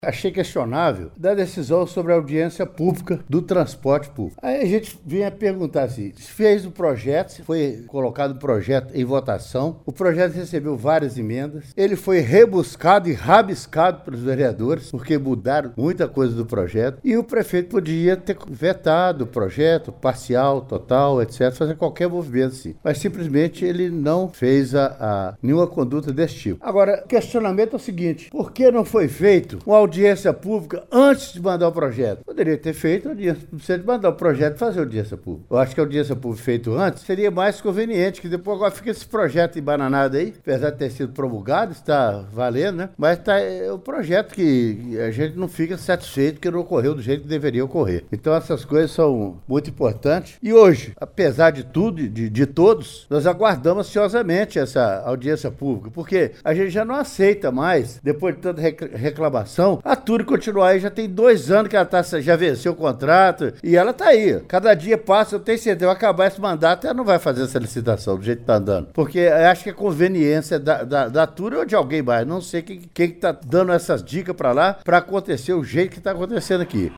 Durante pronunciamento na tribuna livre, o vereador Ênio Talma Ferreira de Rezende (PSDB) disse que da forma como o processo está sendo conduzido é possível que a licitação para escolha da nova empresa não seja feita neste mandato: